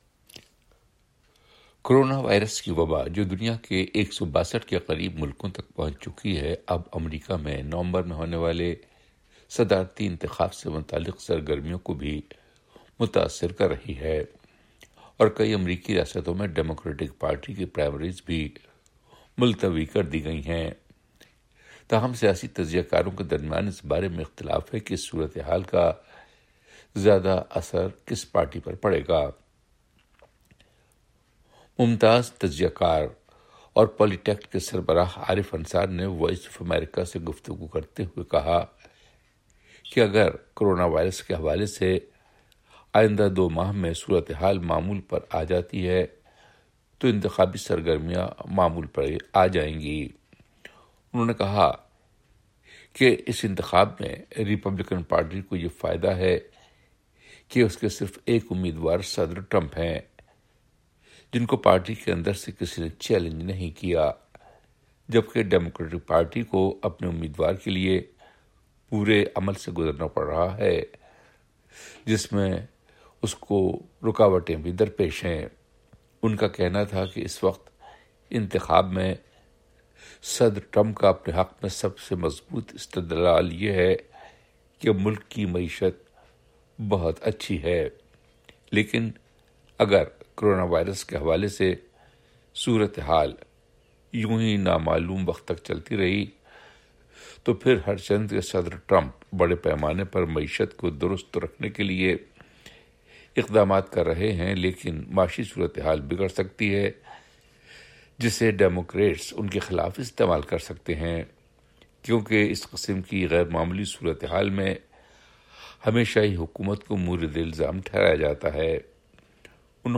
تفیصلی رپورٹ